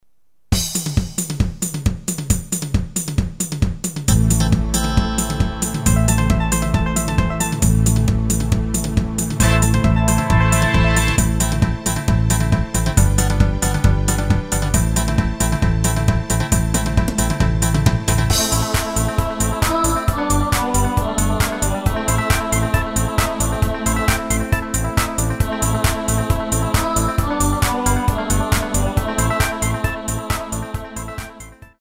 Tempo: 135 BPM.
MP3 with melody DEMO 30s (0.5 MB)zdarma